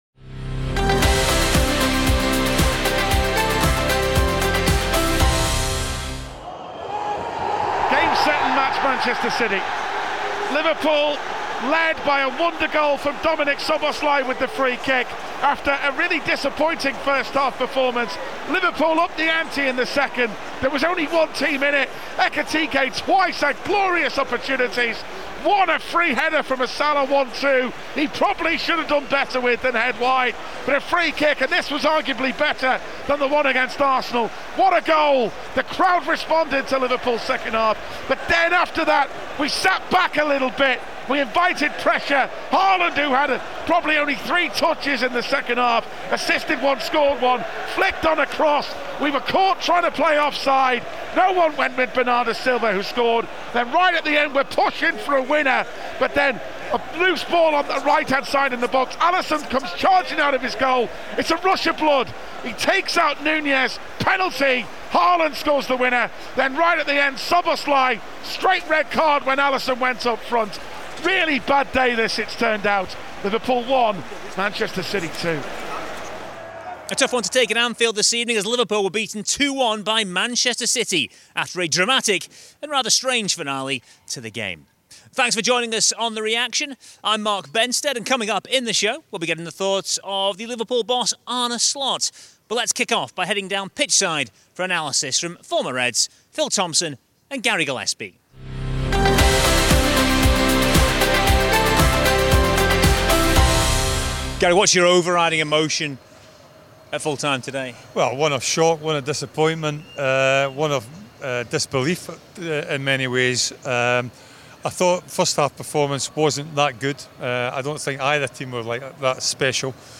Former Reds Phil Thompson and Gary Gillespie also provide pitchside analysis at Anfield.